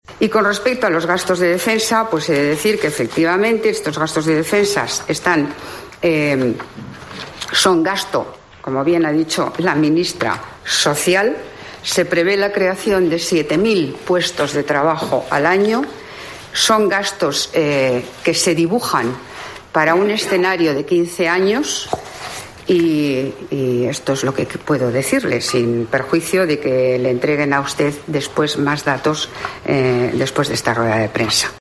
Isabel Celáa tras el consejo de ministros de hoy en el que se dió luz verde a la orden de ejecución